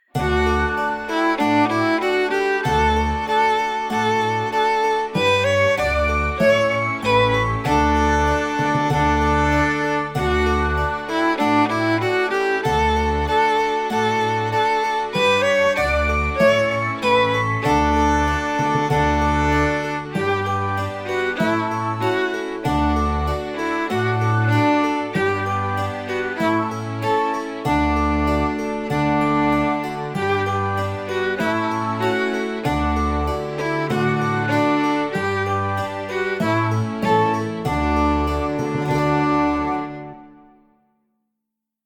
Voicing: Cello w/mp